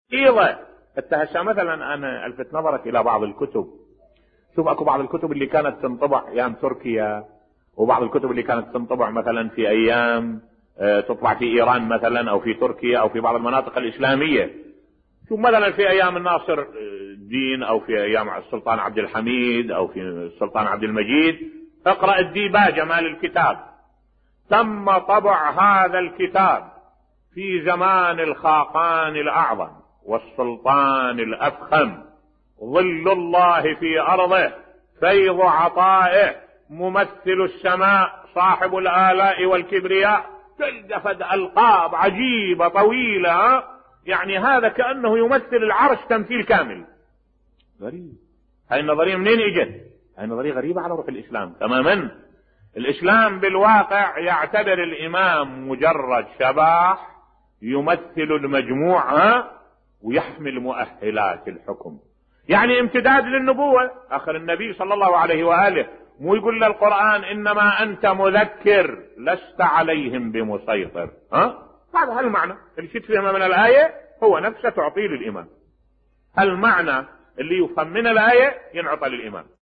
ملف صوتی تهكم من اسلوب الدولة العثمانية في تقديس الحكام بصوت الشيخ الدكتور أحمد الوائلي